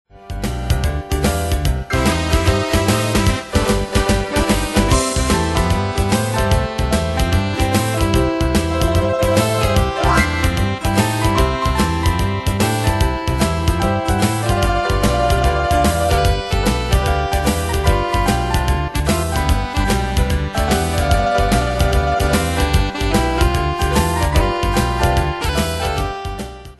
Style: PopAnglo Ane/Year: 1994 Tempo: 148 Durée/Time: 3.58
Danse/Dance: TripleSwing Cat Id.
Pro Backing Tracks